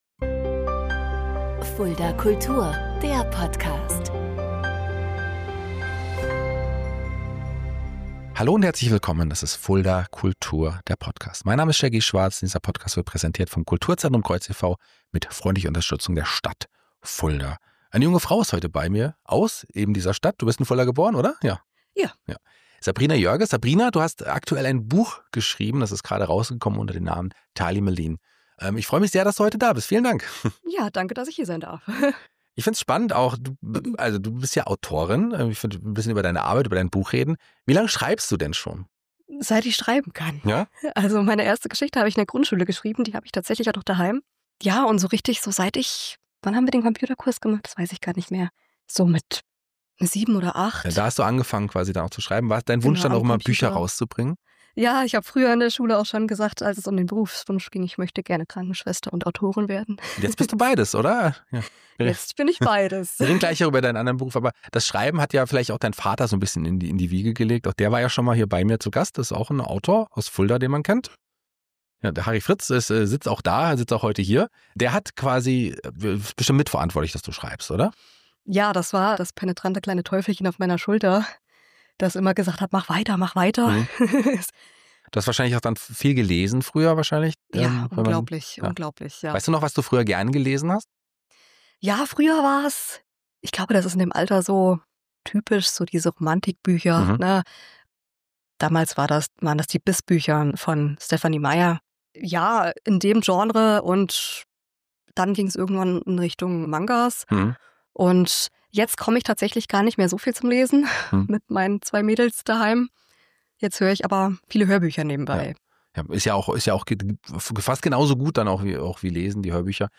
Es geht um schwierige Themen wie Ausgrenzung, innere Kämpfe und die Frage, wie Literatur helfen kann, Erlebtes zu verarbeiten und anderen Mut zu machen. Ein ehrliches Gespräch über Kreativität, Verantwortung beim Schreiben und den Moment, in dem aus einer langen Idee endlich ein veröffentlichtes Buch wird.